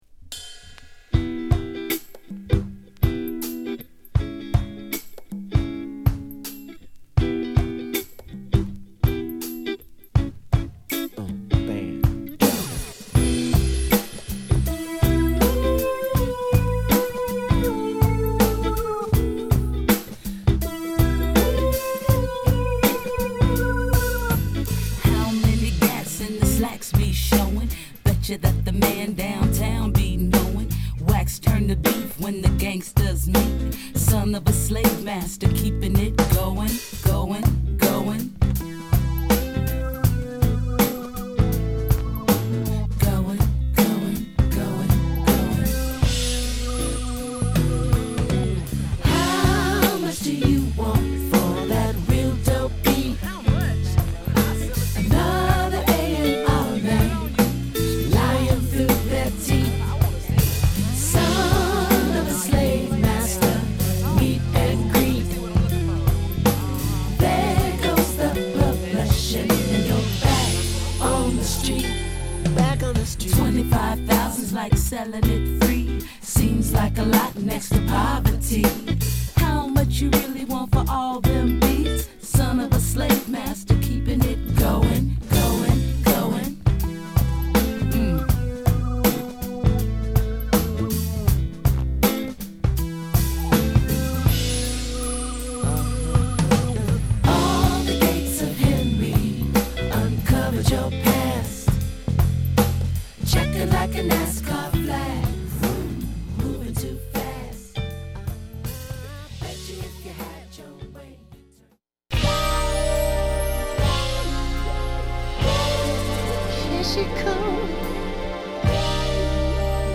2010年にレコーディングされたもののお蔵入りとなっていたスタジオ・アルバム
メロウでソウルな